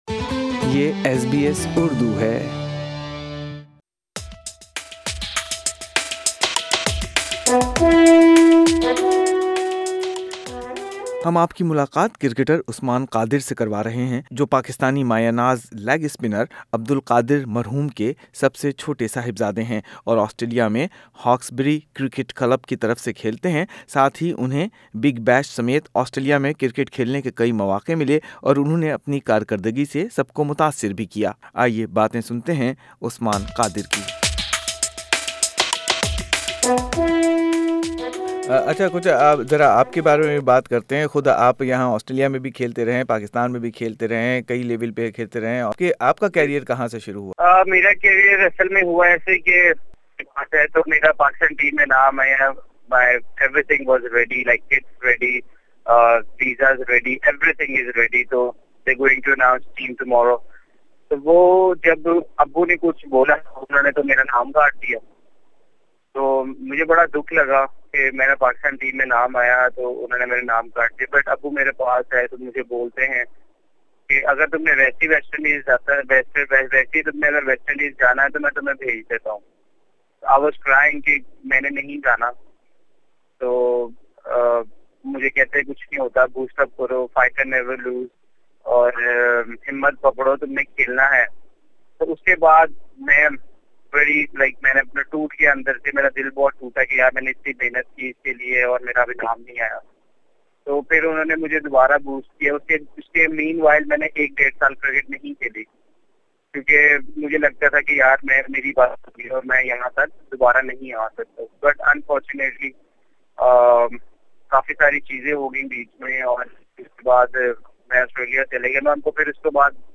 سنئیے باتیں ابھرتے ہوئے کرکٹر عثمان قادر کی جو ہاکسبری کرکٹ کلب کی طرف سے کھیلتے ہیں۔